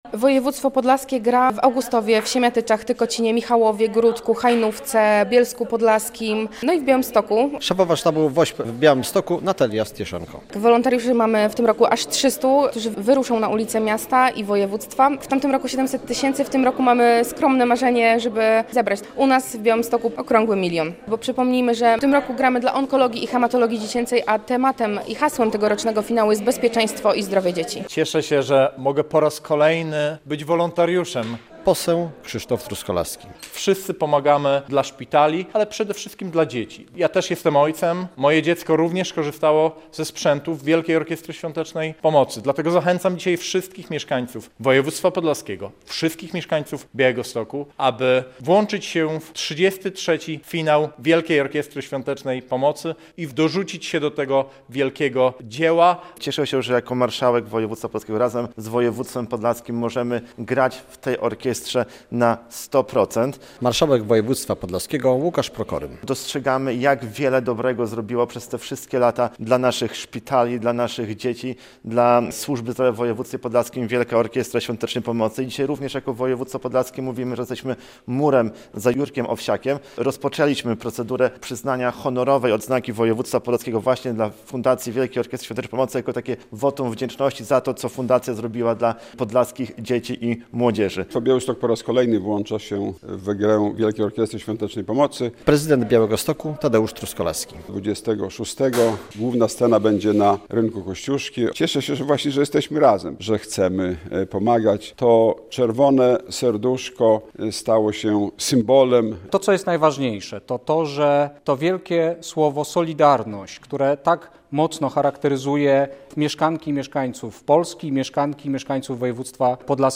Nie ma w regionie szpitala, który nie skorzystałby na wsparciu WOŚP, dlatego rozpoczęliśmy procedurę przyznania Honorowej Odznaki Województwa Podlaskiego Fundacji WOŚP - mówi marszałek województwa Łukasz Prokorym.
Obecny na konferencji prasowej wojewoda podlaski Jacek Brzozowski zaznaczył, że orkiestra jednoczy ludzi i pokazuj czym jest "solidarność".